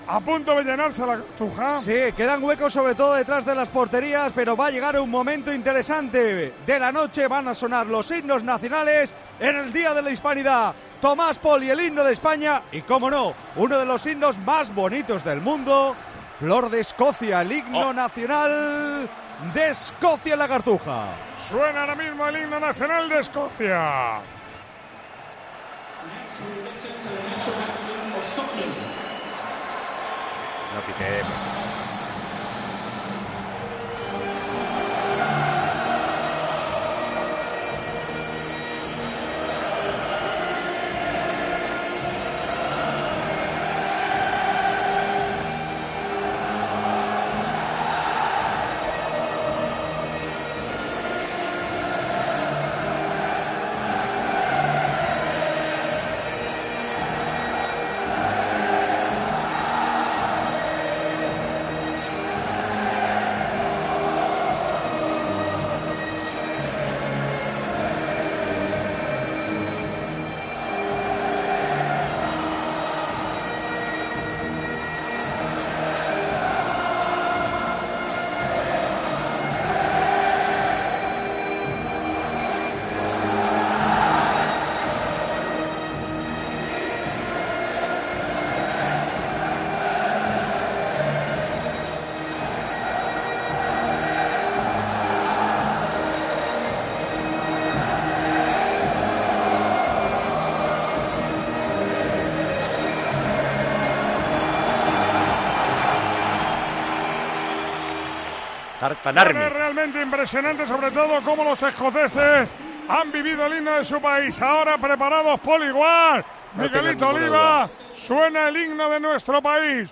Paco González bromeaba en Tiempo de Juego tras escuchar los problemas que tuvo el numeroso público en La Cartuja para entonar al unísono la versión larga del himno español.
Una vez escuchado el himno de la Selección escocesa, perfectamente coreado por los 5000 aficionados que han viajado hasta la capital hispalense, se interpretó el himno de España.
Sonó la versión larga, pero una vez más, una gran parte del público entonó el estribillo cuando comenzaba la segunda estrofa, lo que hizo que ya se perdiera el hilo.
ESCUCHA LA INTERPRETACIÓN DE LOS HIMNOS ANTES DEL ESPAÑA - ESCOCIA, TAL COMO LO ESCUCHAMOS EN TIEMPO DE JUEGO